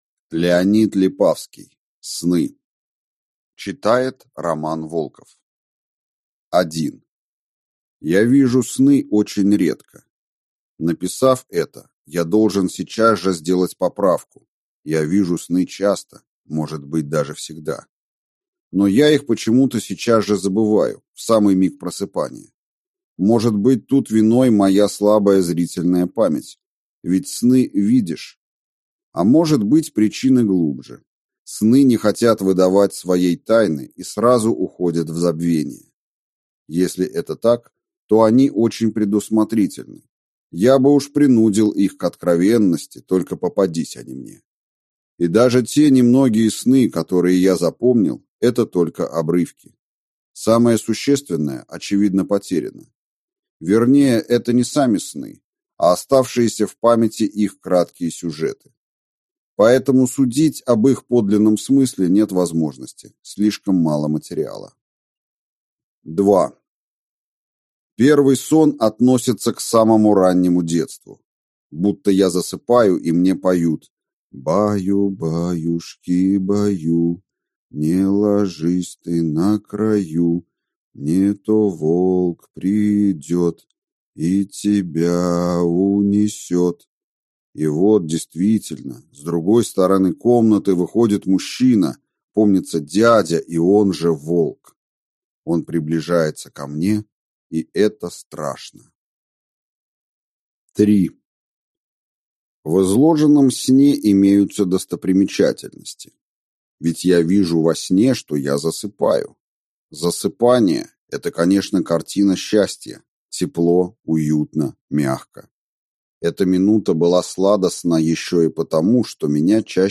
Аудиокнига Сны | Библиотека аудиокниг